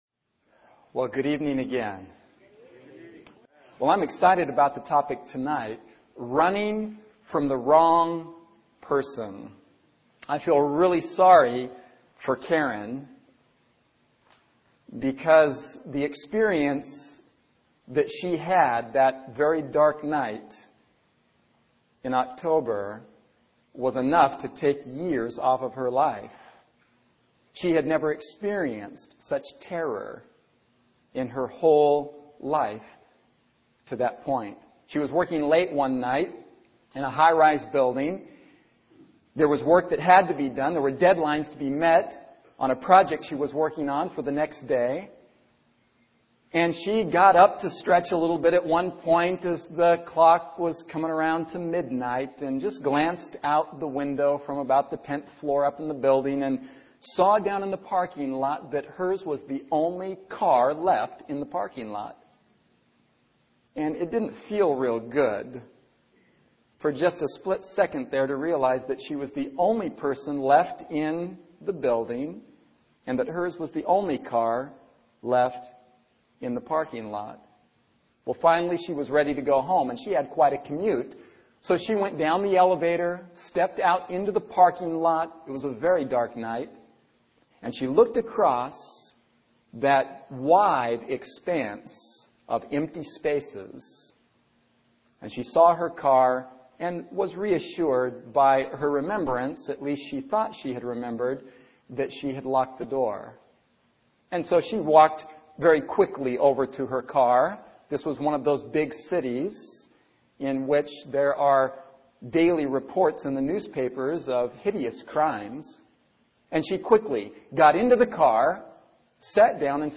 One of the most beautiful sermons I've ever heard!